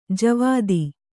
♪ javādi